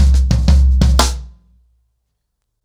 Wireless-90BPM.13.wav